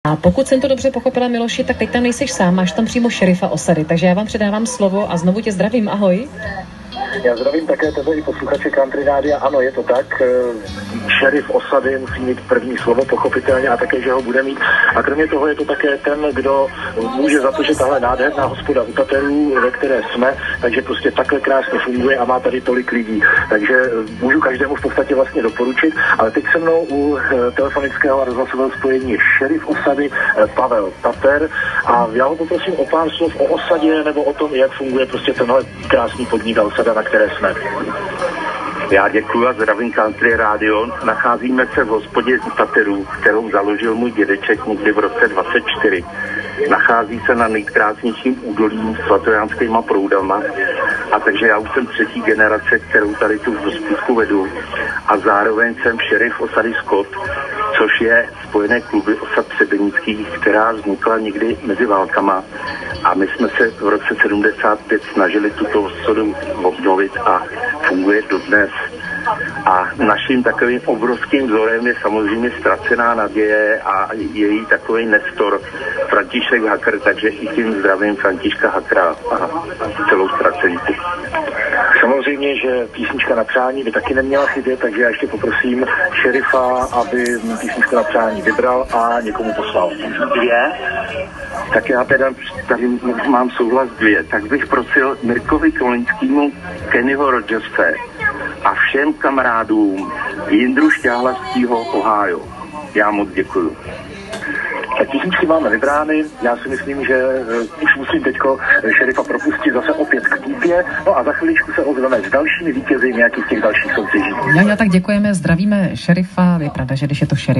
Záznam rozhovoru šerifa z přímého přenosu.